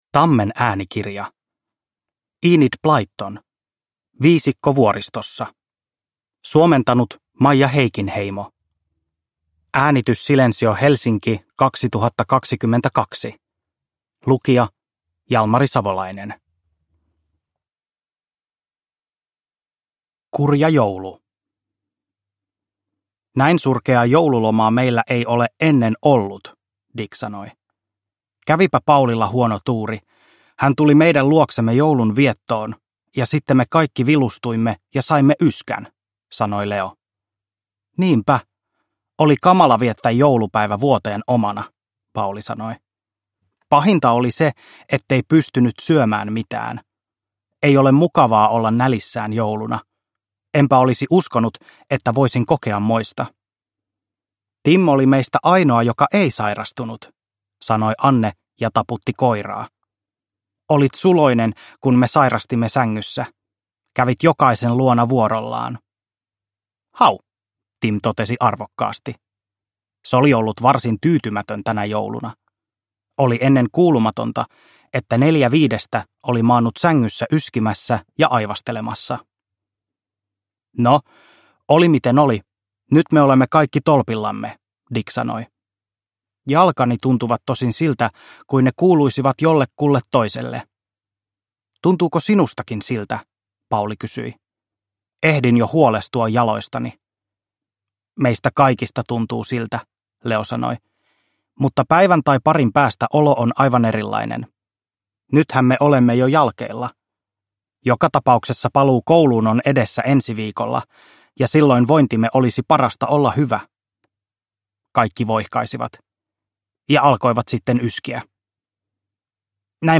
Viisikko vuoristossa – Ljudbok – Laddas ner